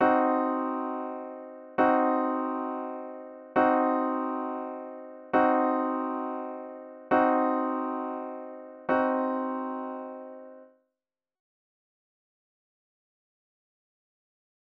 1. Listen to the diminished chord several times.
C Diminished Chord Repeated
C-diminished-repeated.mp3